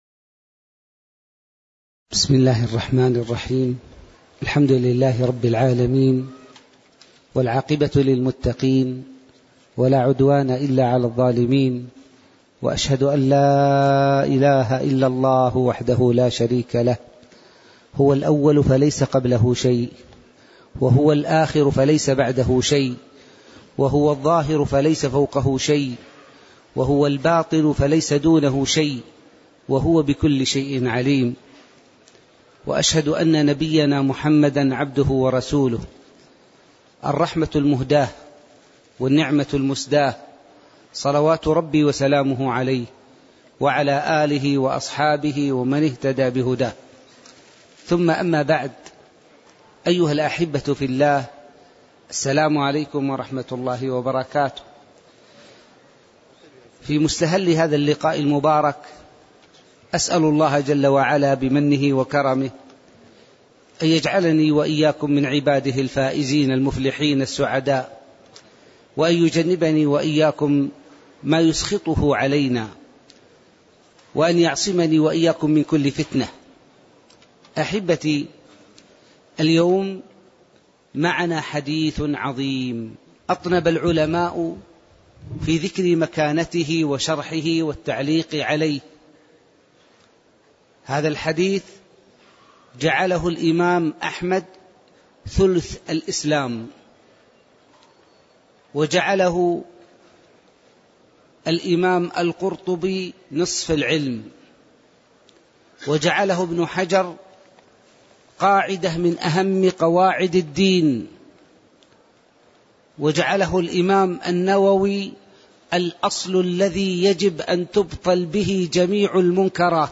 تاريخ النشر ٧ جمادى الآخرة ١٤٣٧ هـ المكان: المسجد النبوي الشيخ